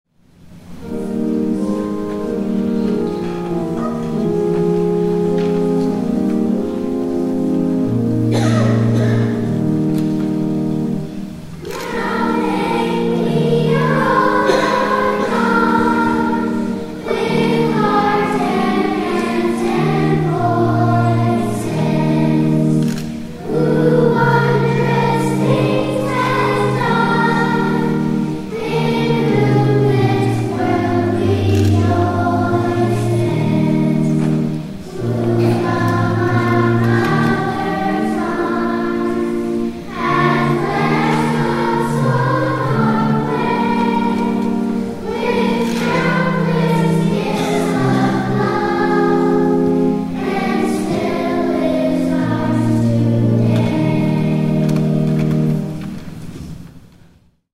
THE CHORAL ASSURANCE OF PARDON